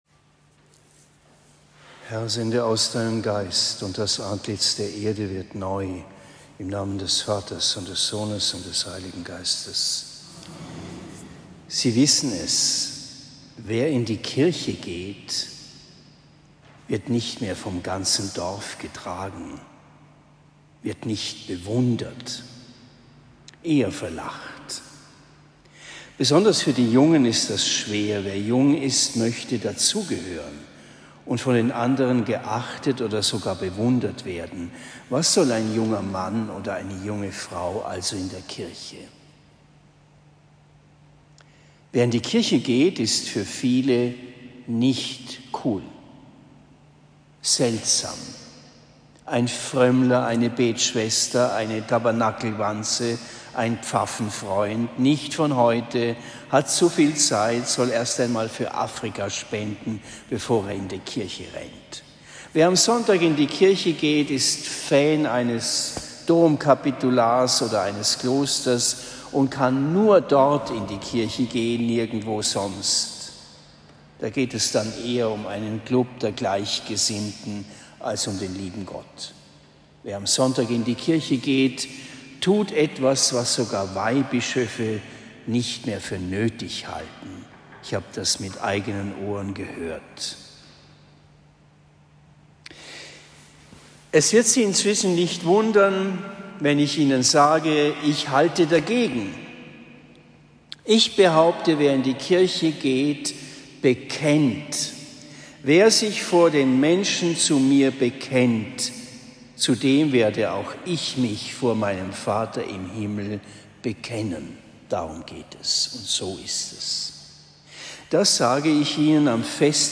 Predigt in Oberndorf am 22. September 2023